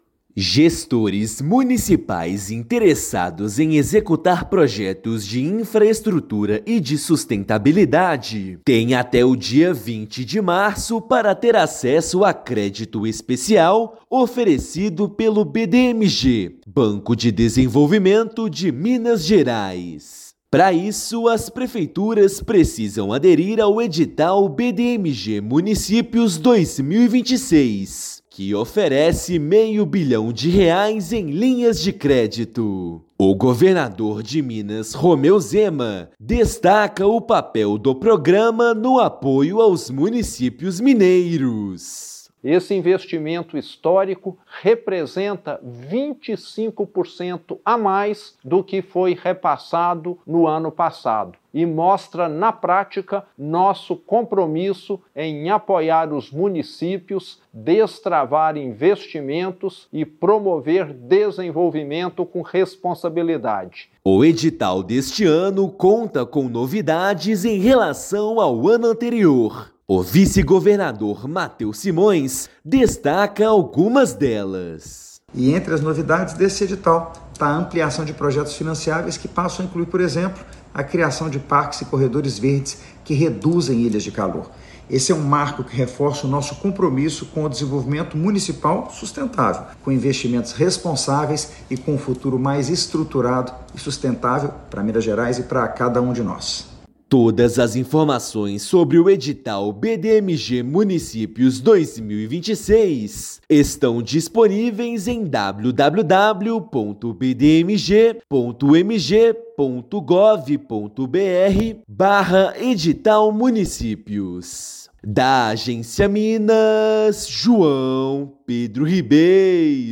Com novos itens financiáveis, banco mineiro de desenvolvimento vai disponibilizar o maior volume de crédito da história do edital, com até dez anos para pagamento. Ouça matéria de rádio.